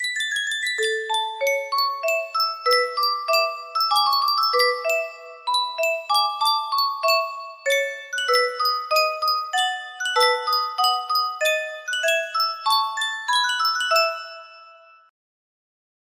Yunsheng Music Box - Boccherini Minuet 5734 music box melody
Full range 60